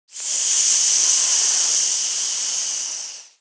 00134_Sound_SERPENT.mp3